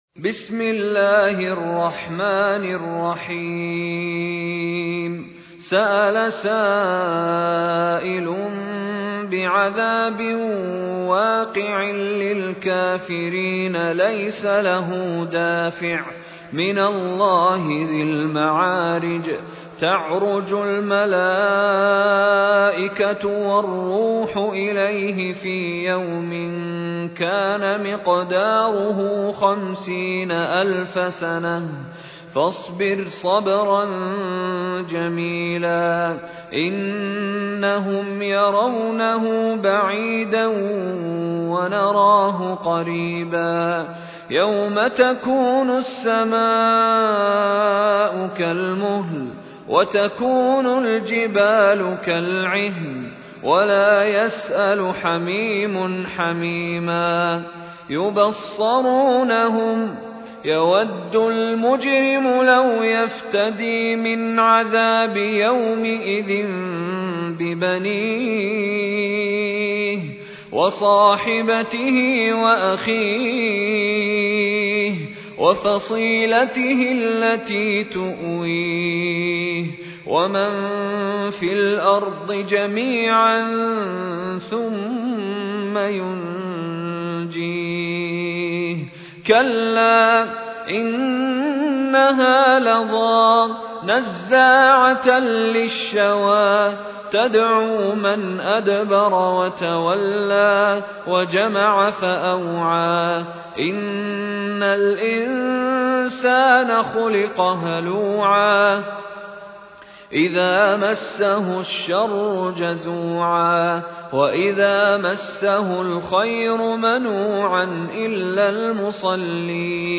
المصاحف - مشاري بن راشد العفاسي
المصحف المرتل - حفص عن عاصم